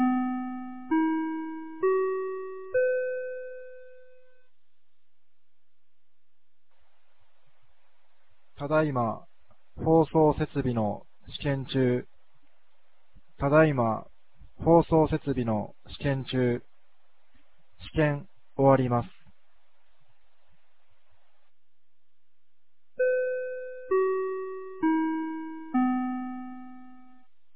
2025年08月23日 16時04分に、由良町から全地区へ放送がありました。
放送音声